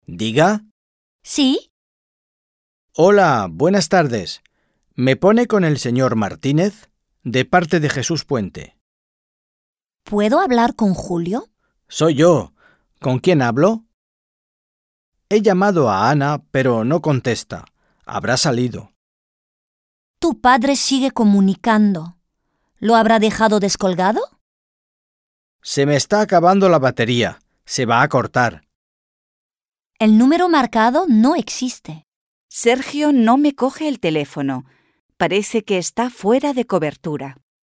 Un peu de conversation - Le téléphone